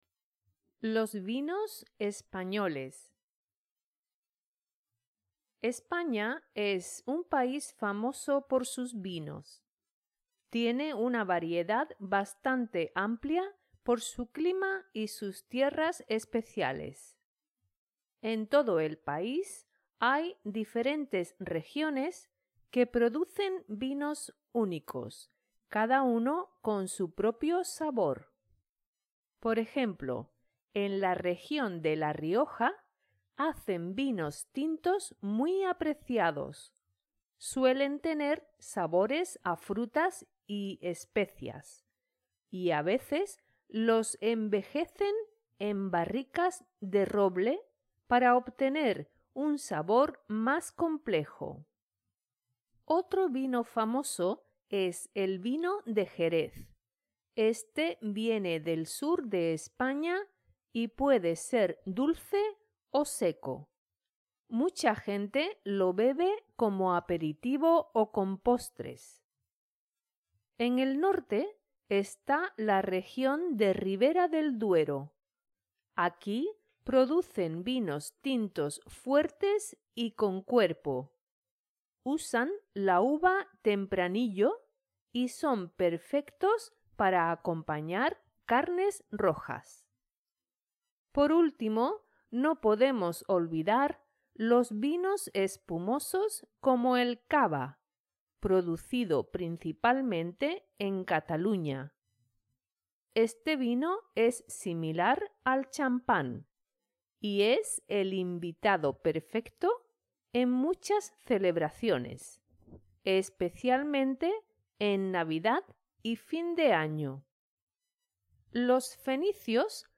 Spanish online reading and listening practice – level A2